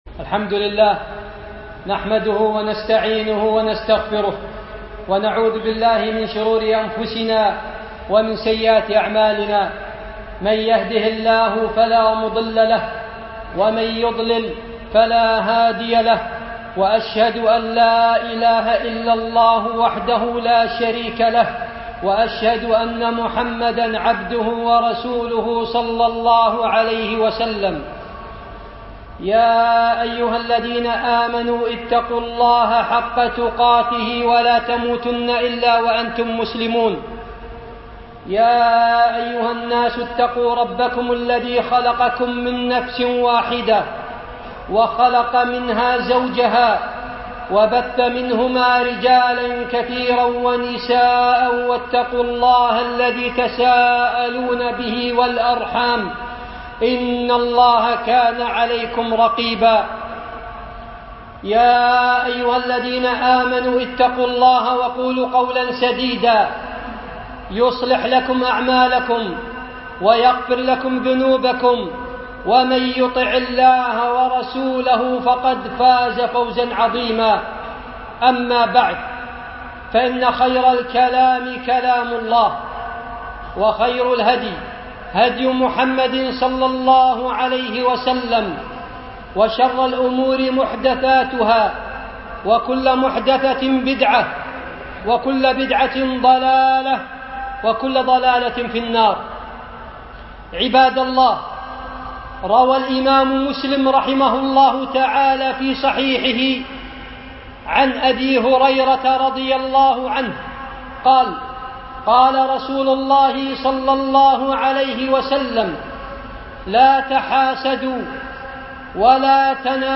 وصايا نبوية - خطبة